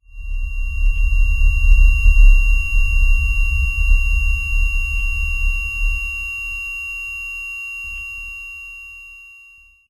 Ported the ACE2 ear ringing logic
muzzleblast_ring2.ogg